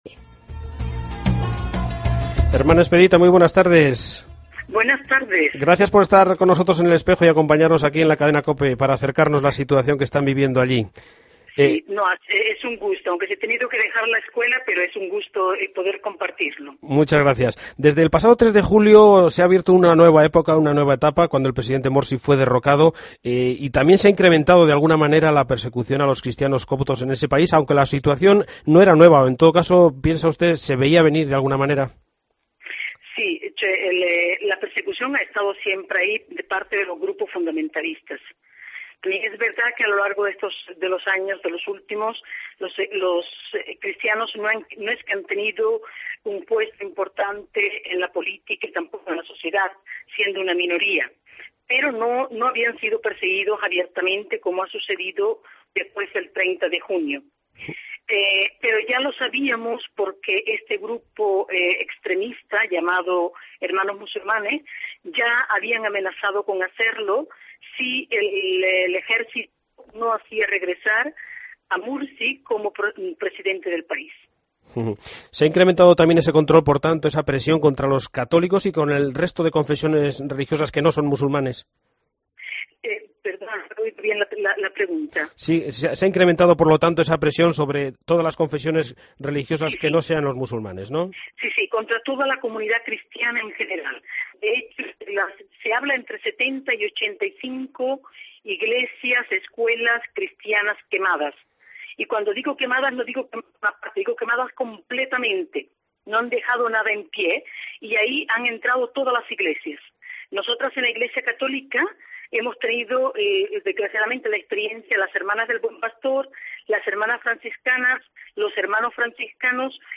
AUDIO: Entrevista a la misionera española en Egipto, la hermana